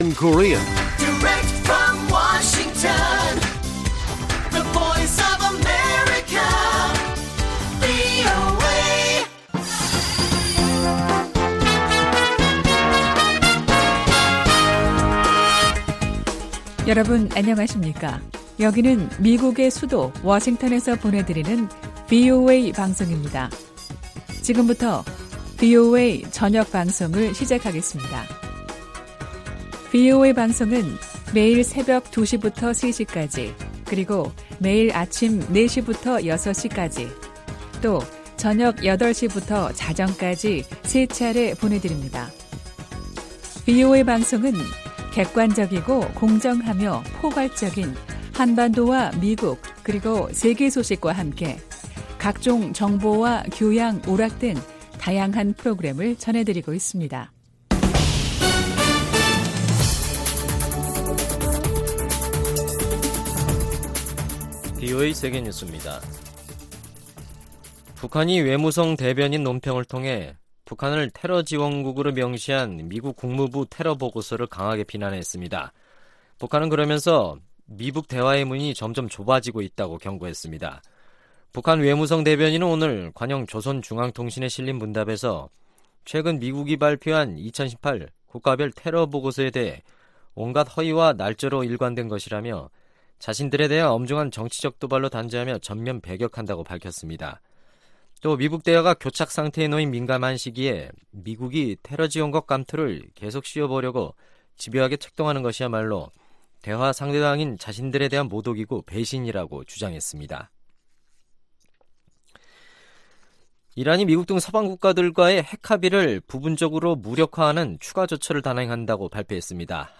VOA 한국어 간판 뉴스 프로그램 '뉴스 투데이', 2019년 11월 5일 1부 방송입니다. 미-북 협상에 진전이 없는 가운데, 미국 의회 일각에서는 북한 문제에 대한 ‘다음 단계’를 논의해야 한다는 목소리가 나오고 있습니다. 서울에서 열린 국제 포럼에서 전문가들은 북 핵 문제 해결과 지역의 평화를 위해 다자 협력이 필요하다는 데 의견을 같이 했습니다.